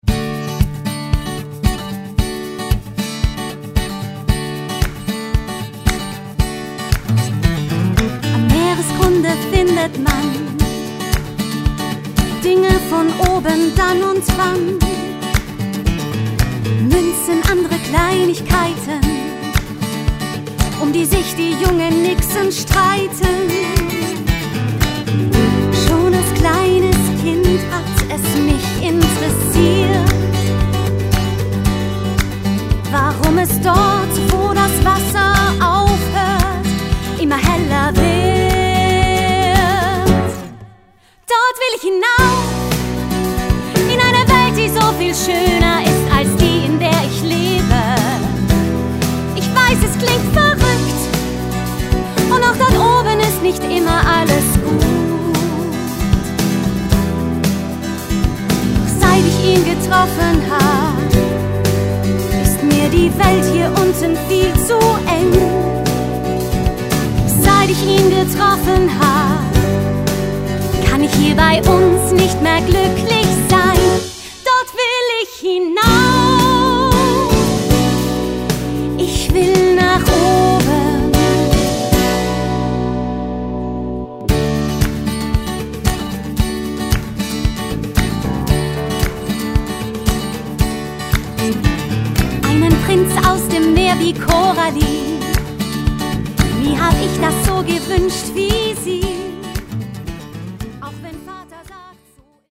Ein Musical über Liebe, Sehnsucht und das Erwachsenwerden.